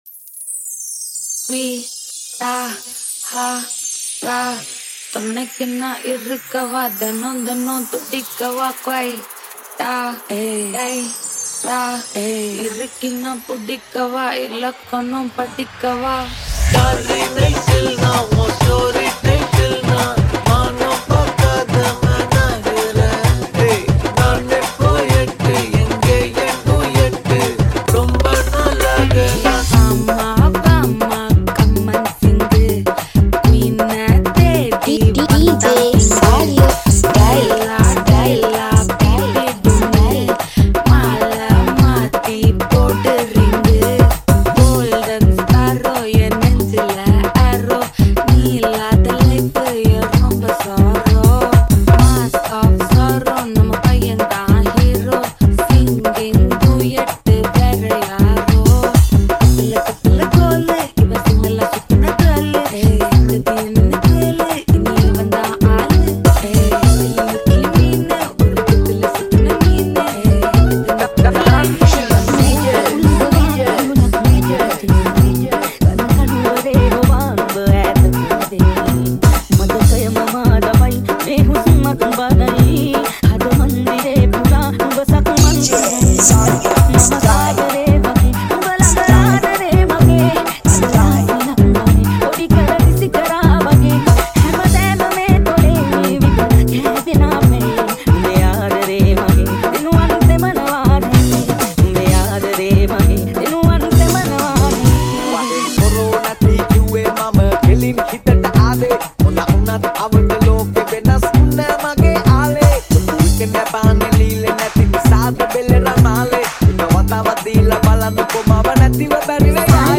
Dj Nonstop